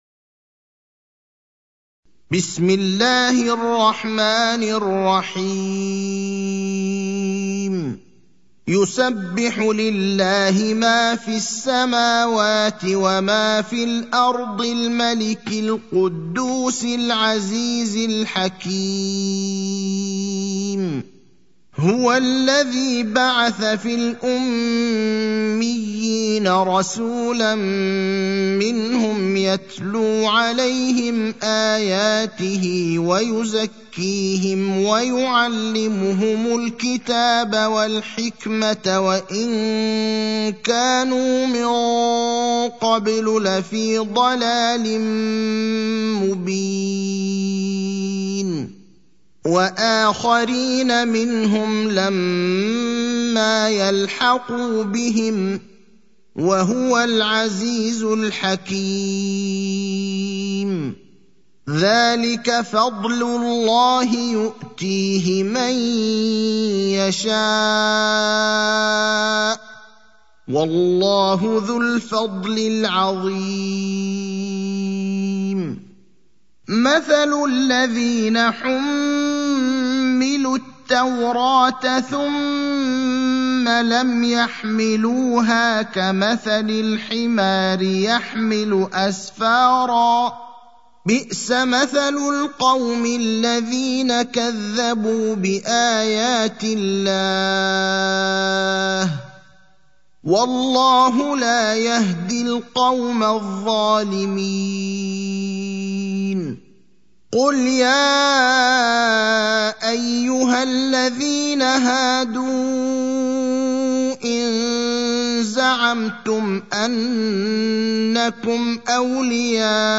المكان: المسجد النبوي الشيخ: فضيلة الشيخ إبراهيم الأخضر فضيلة الشيخ إبراهيم الأخضر الجمعة (62)